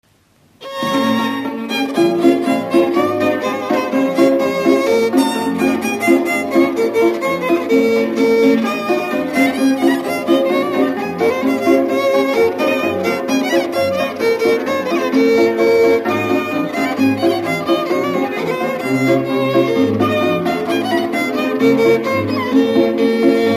Dallampélda: Hangszeres felvétel
Alföld - Szatmár vm. - Rozsály
hegedű
cimbalom
brácsa
bőgő
Műfaj: Oláhos
Stílus: 7. Régies kisambitusú dallamok
Kadencia: b3 (1) b3 1